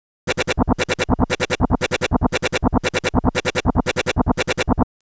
The sound samples below are sequences consisting of two different types of sounds.
The sequences are always the same: a basic pattern AABBA of the two types of sound is recycled over time (i.e. AABBAAABBAAABBAAABBAAABBA...).
In the first case, several dimensions (brightness of timbre, loudness, frequency separation) have been manipulated.
In fact, if you focus your attention to sound A, then you'll perceive a "AAA--" pattern, whereas the B sound is perceived as a "---BB" stream.